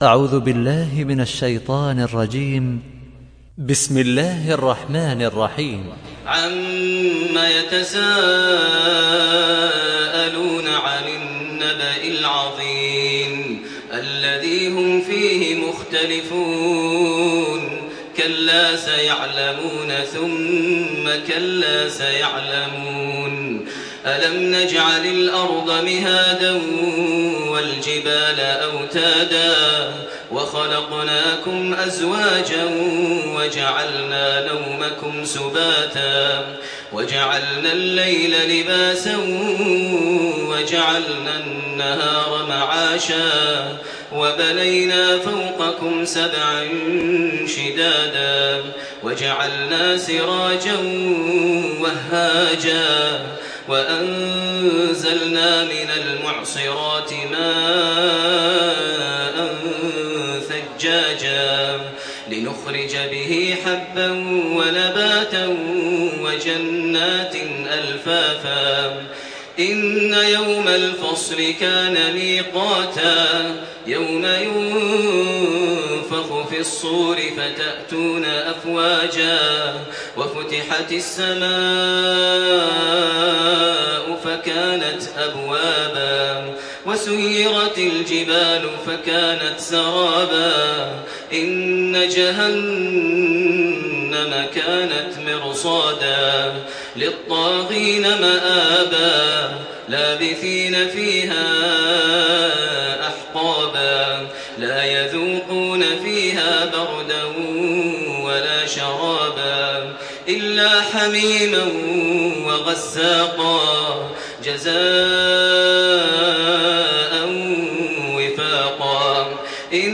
سورة النبأ MP3 بصوت تراويح الحرم المكي 1428 برواية حفص عن عاصم، استمع وحمّل التلاوة كاملة بصيغة MP3 عبر روابط مباشرة وسريعة على الجوال، مع إمكانية التحميل بجودات متعددة.
تحميل سورة النبأ بصوت تراويح الحرم المكي 1428
مرتل